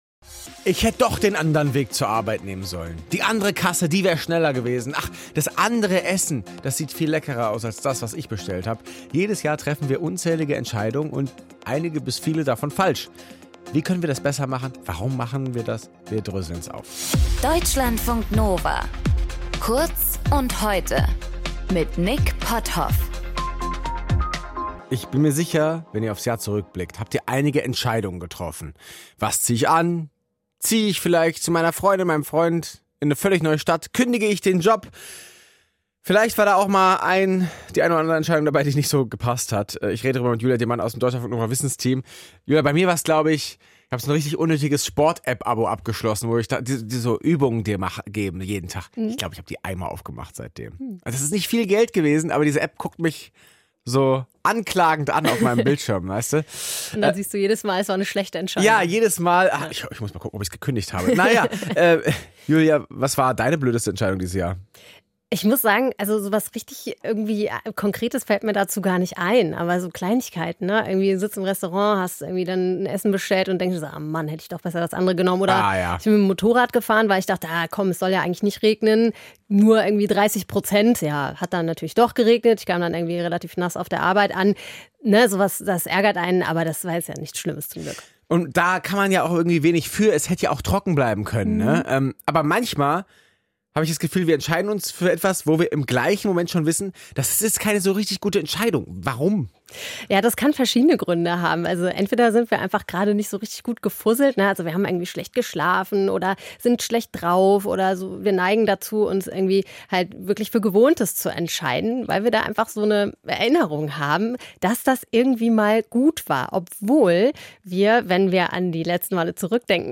Moderation:
Gesprächspartnerin: